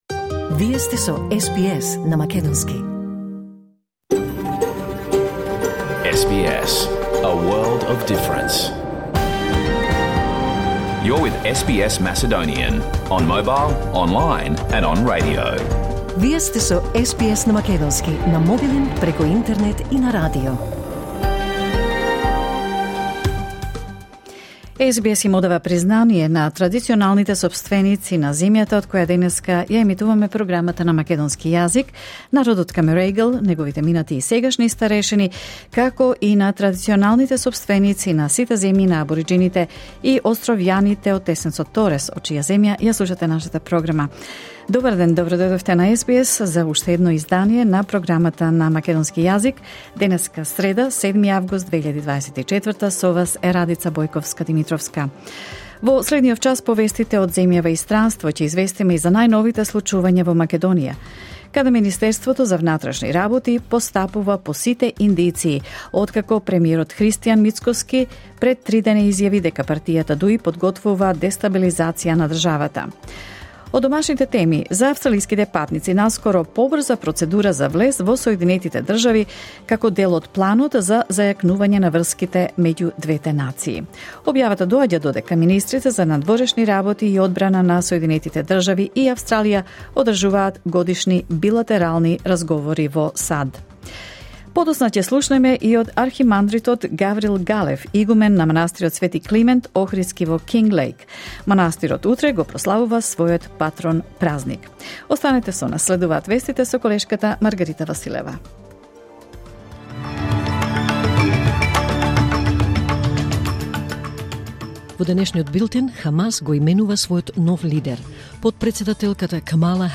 SBS Macedonian Program Live on Air 7 August 2024